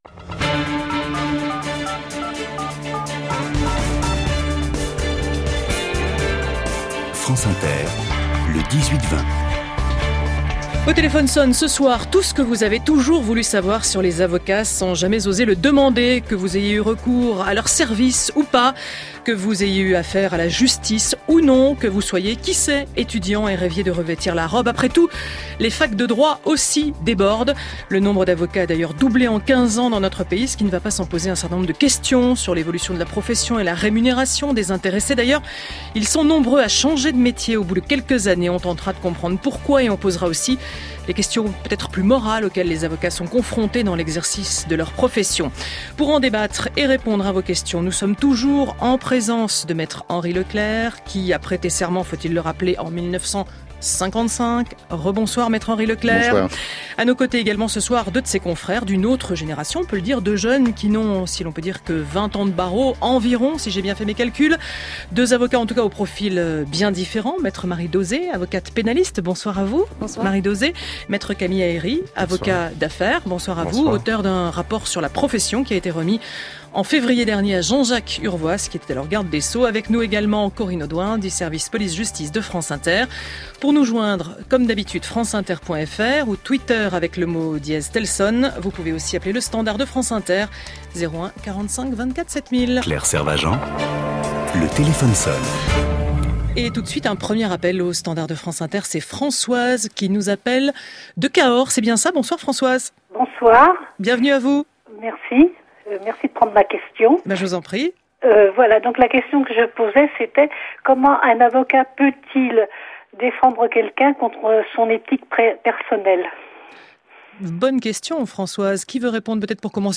Diffusé sur France Inter le 6 octobre 2017